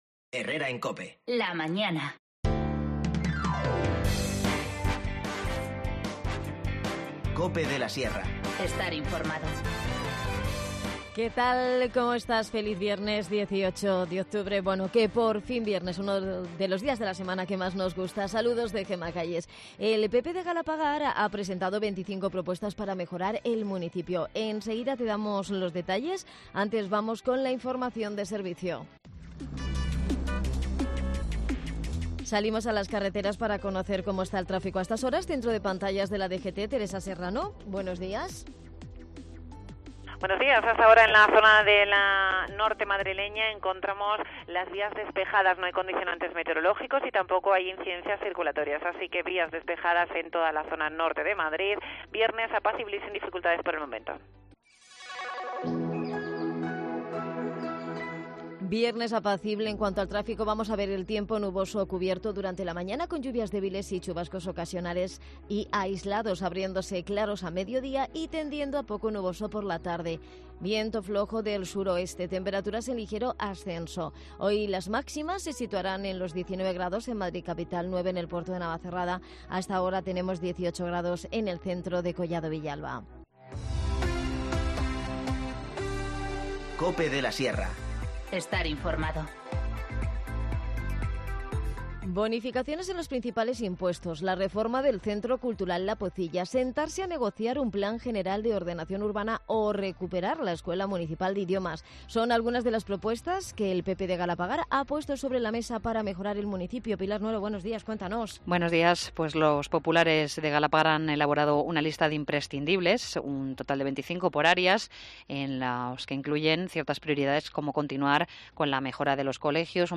Nos visita Adan Martínez, concejal de comuninación en Collado Villalba, para hablarnos de las actividades que han preparado con motivo del Día Mundial Contra el Cáncer de Mama y repasamos las citas culturales para estos días.